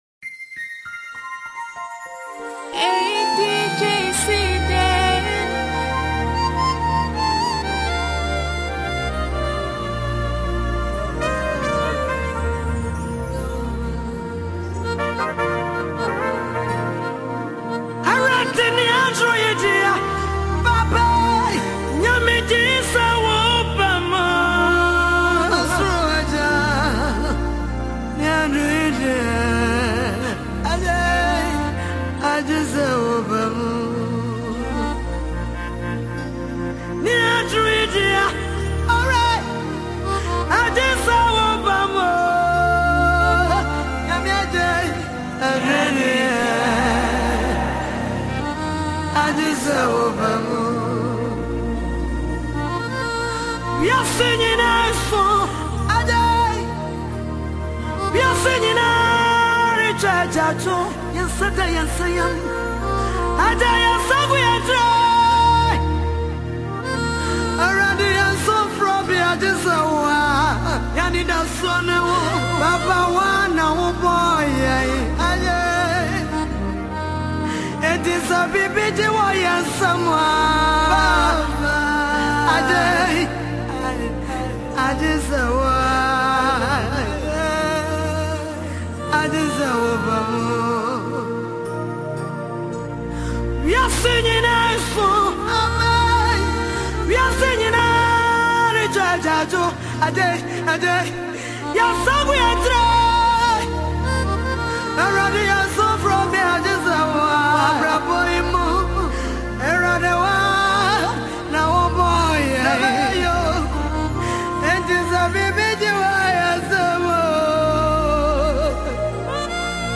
gospel mixtape
Genre: Mixtape